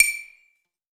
SOUTHSIDE_percussion_small_hit.wav